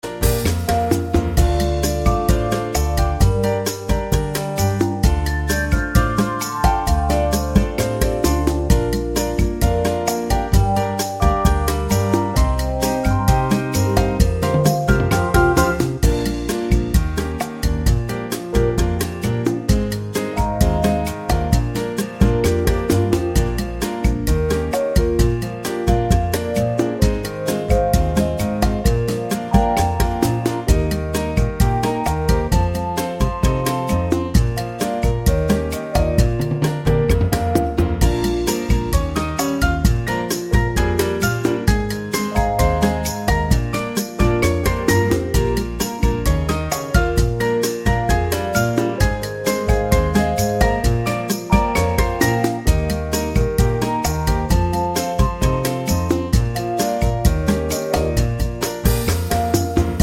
Up 3 Semitones For Female